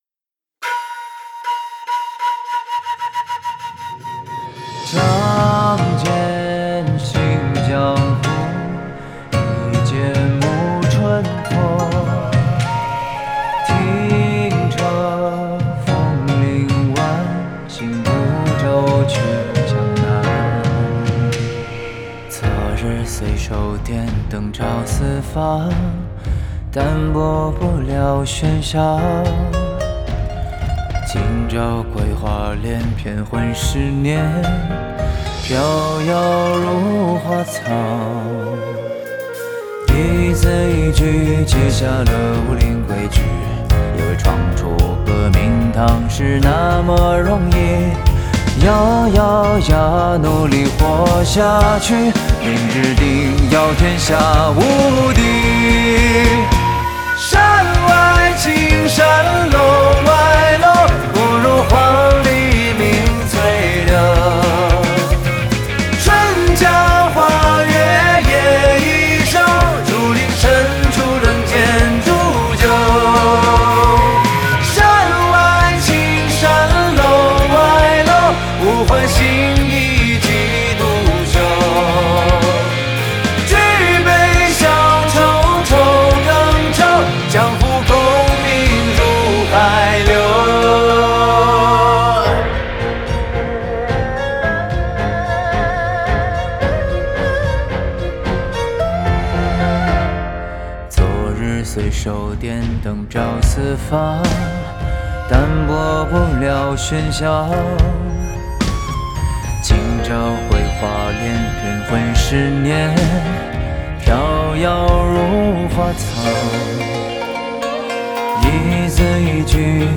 Ps：在线试听为压缩音质节选，体验无损音质请下载完整版
吉他
笛子/箫
和声